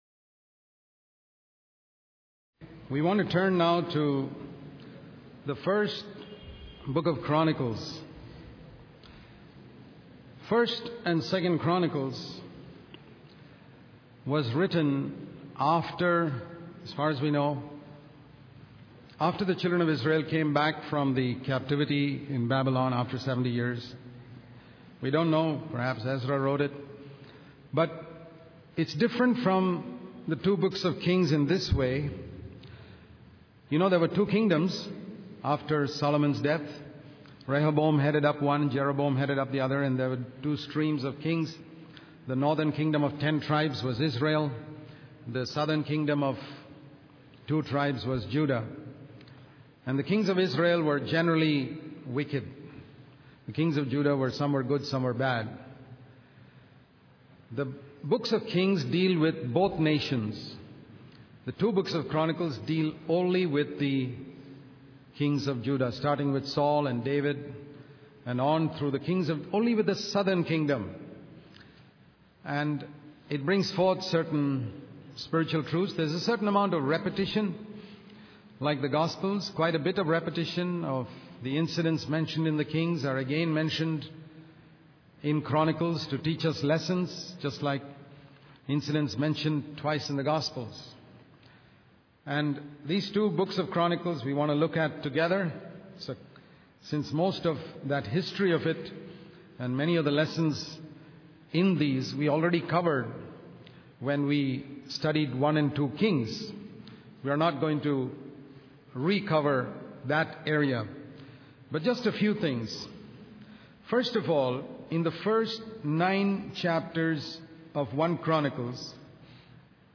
In this sermon, the speaker focuses on the book of Chronicles, specifically the first nine chapters.